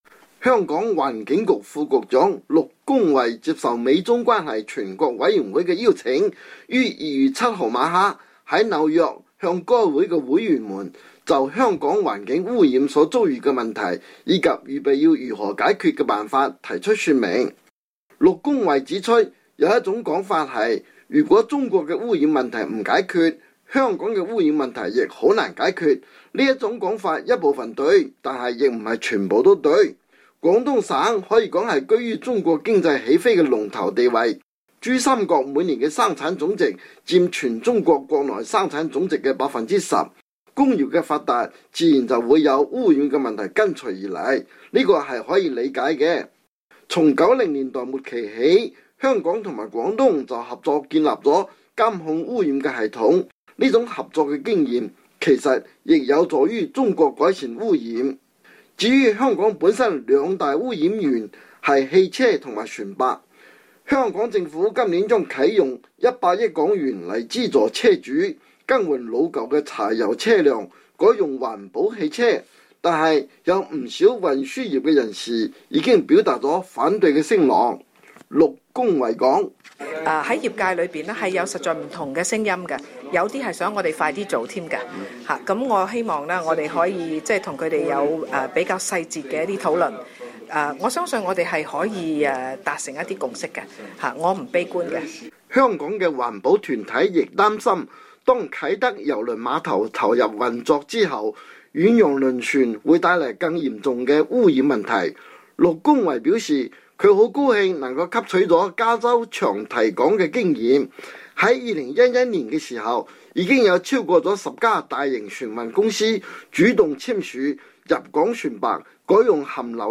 香港環境局副局長陸恭蕙接受美中關係全國委員會的邀請,於2月7日晚間在紐約向該會的會員就香港環境污染所遭遇的問題,及預備要如何解決的辦法提出說明。